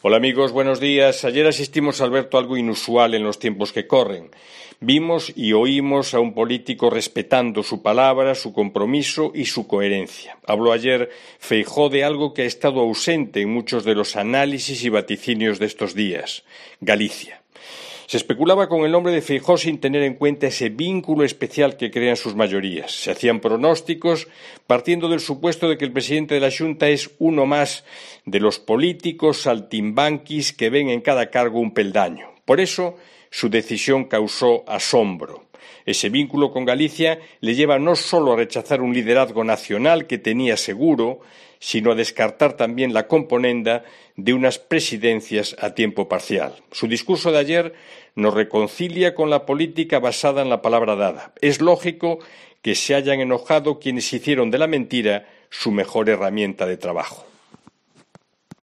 En su comentario diario en Cope Galicia